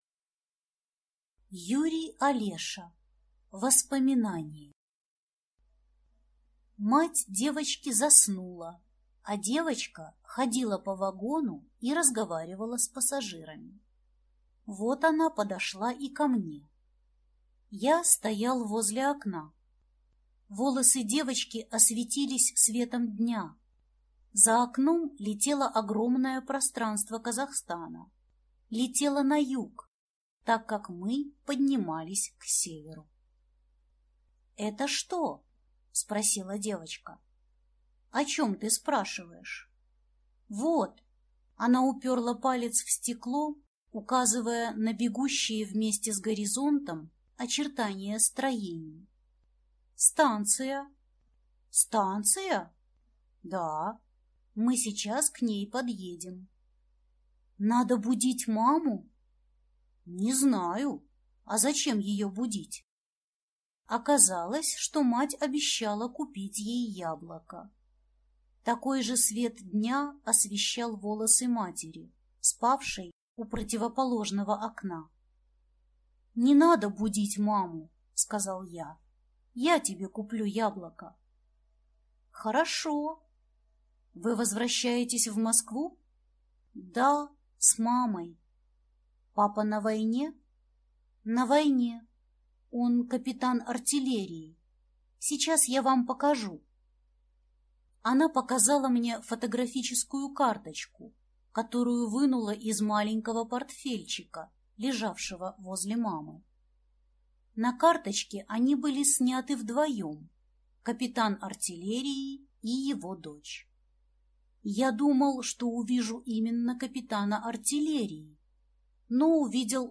Аудиокнига Воспоминание | Библиотека аудиокниг